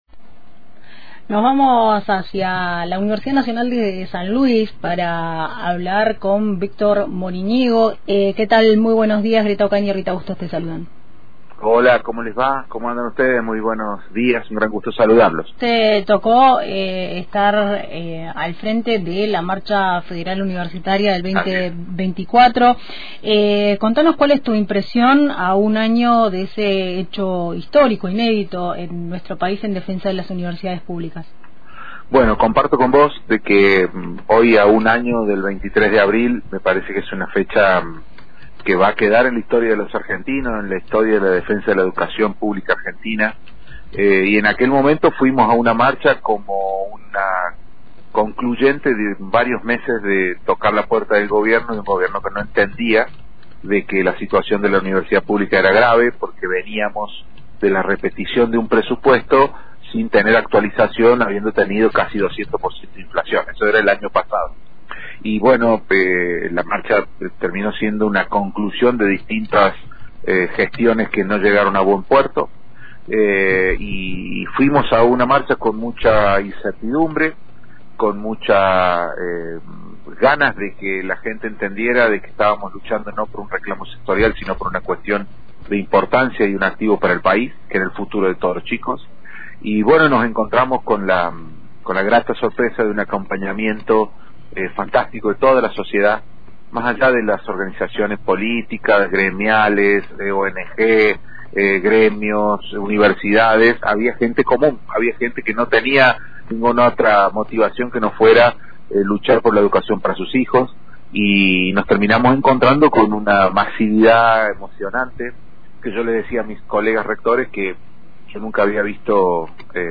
Víctor Moriñigo, ex presidente del Consejo Interuniversitario Nacional, repasó en Antena Libre el primer aniversario de la multitudinaria marcha del 23 de abril de 2024.